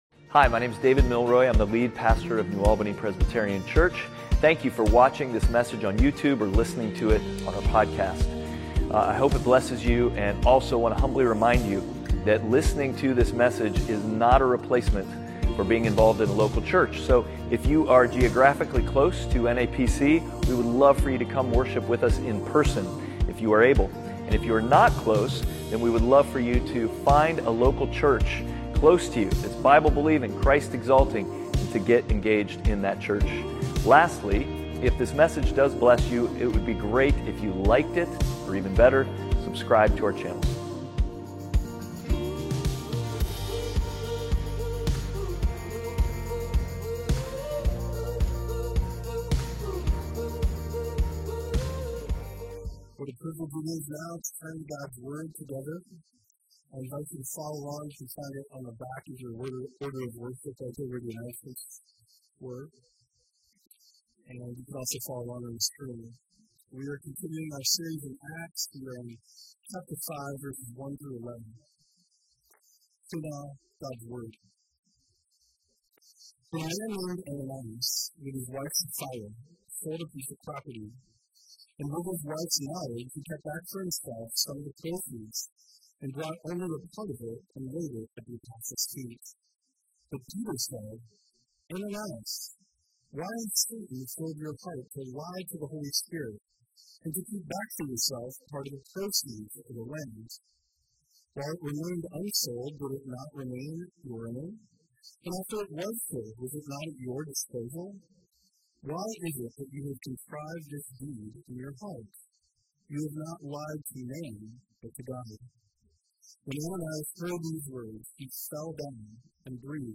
Acts 5:1-11 Service Type: Sunday Worship « Outward